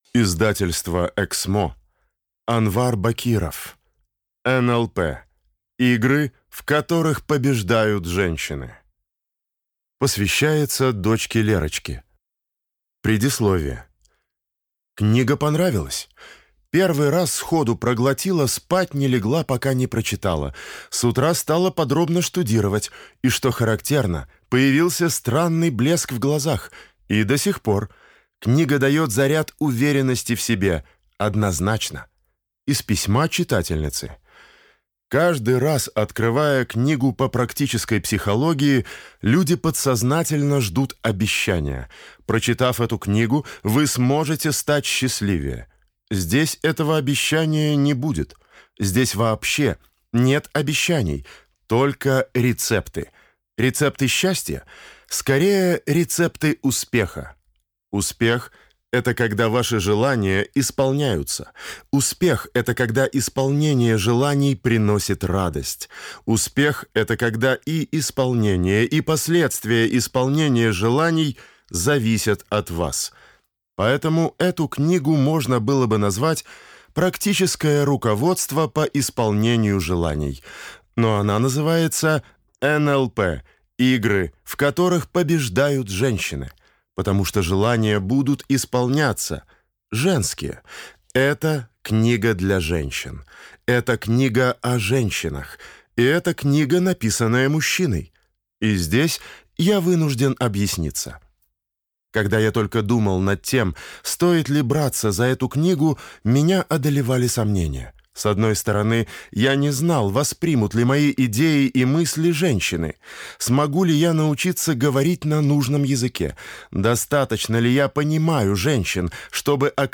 Аудиокнига НЛП. Игры, в которых побеждают женщины | Библиотека аудиокниг